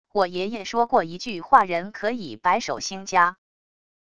我爷爷说过一句话――人可以白手兴家wav音频生成系统WAV Audio Player